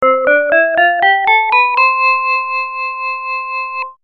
1.ベルの様な音色
crfmbell.mp3